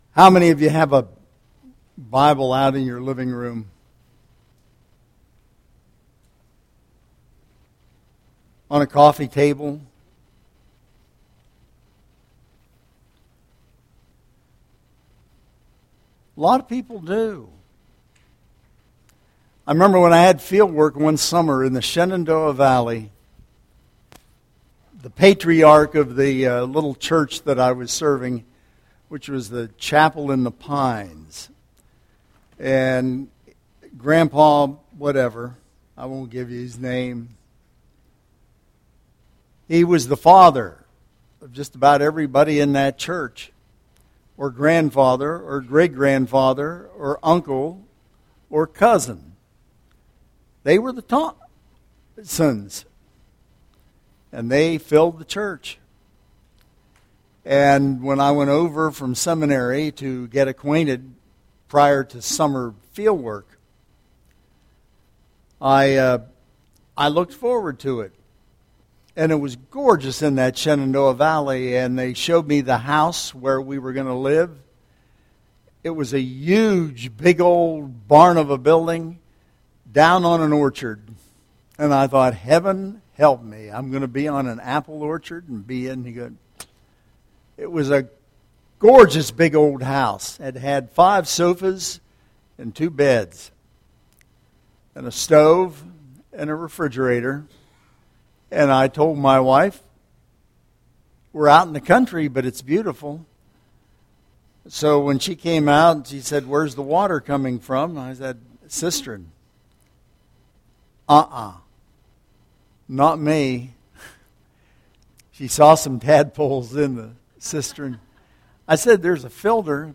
Phillipians :: Sermon Tags :: McLeod Memorial Presbyterian Church